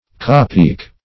Cappeak \Cap"peak`\, n. The front piece of a cap; -- now more commonly called visor.